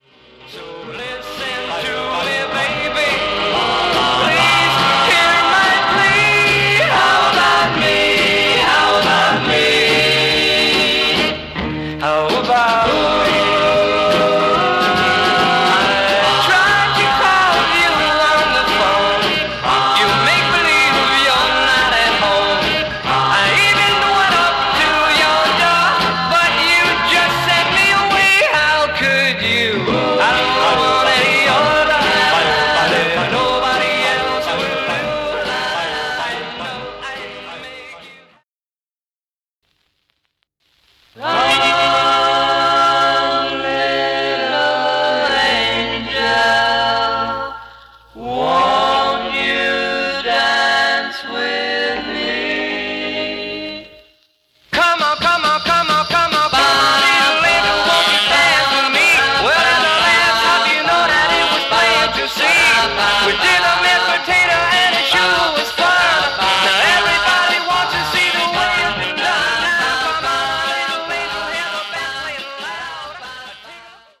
2 sides sampler
Genre: Doowop/Vocal Groups